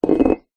Стеклянная банка из-под сметаны оказалась на столе